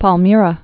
(päl-mîrə, -mērä)